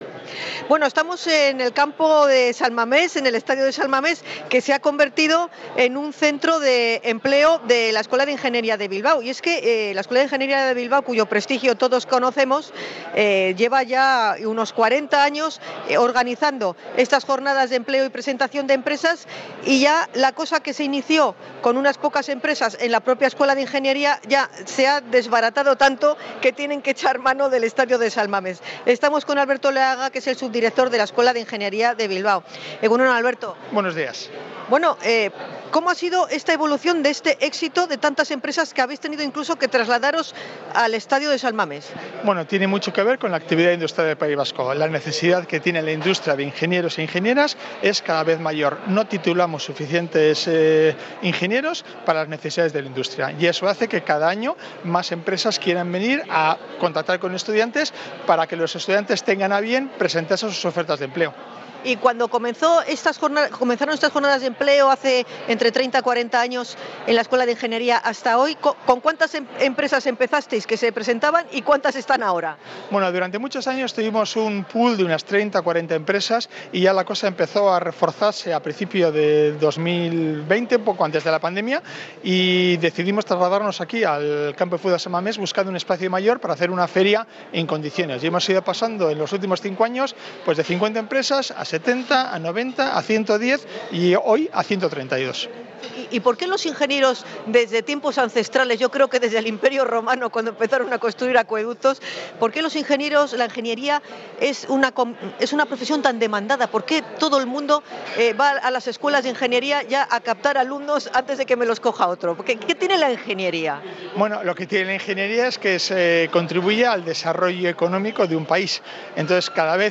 Hemos estado en la Jornada de Empleo de la Escuela de Ingeniería de Bilbao
REPORTAJE-EMPLEO-INGENIERIA.mp3